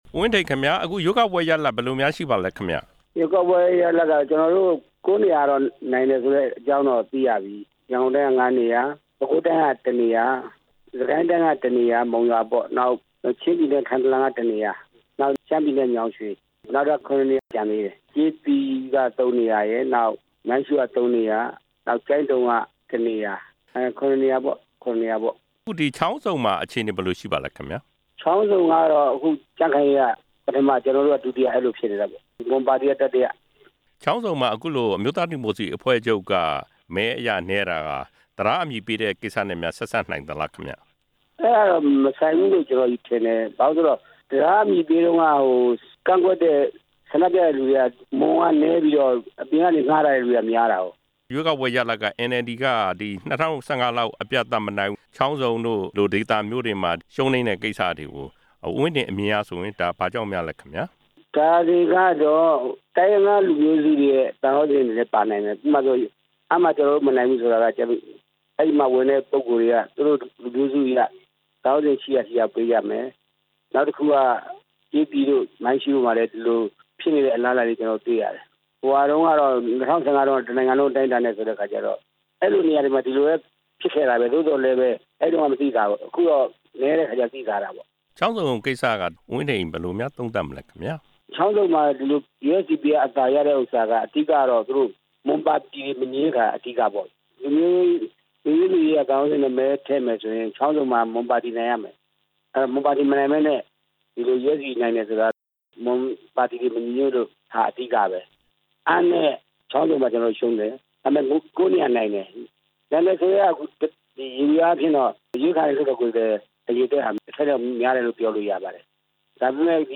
ဦးဝင်းထိန်နဲ့ မေးမြန်းချက်